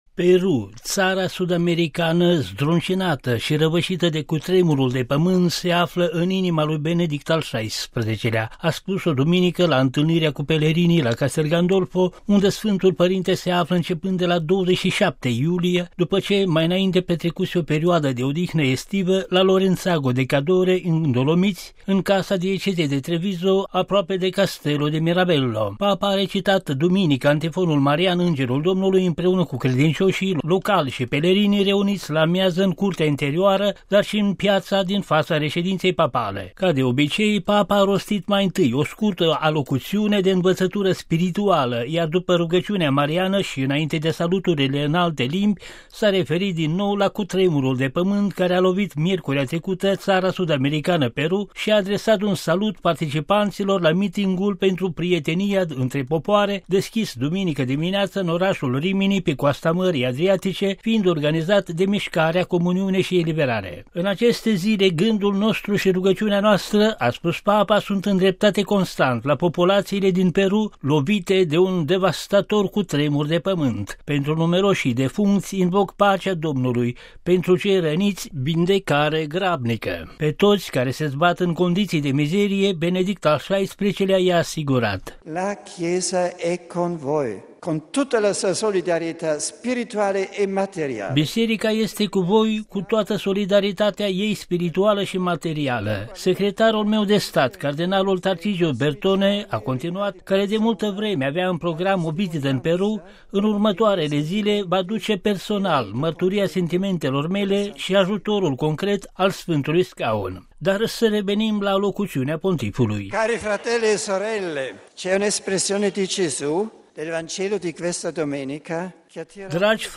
Papa a recitat duminică antifonul marian „Îngerul Domnului” împreună cu credincioşii, locali şi pelerini, reuniţi la amiază în curtea interioară dar şi în paiaţa din faţa reşedinţei papale.
Ascultaţi şi binecuvântarea apostolică invocată de Papa la întâlnirea cu pelerinii duminică la Castel Gandolfo reuniţi pentru recitarea rugăciunii „Îngerul Domnului”.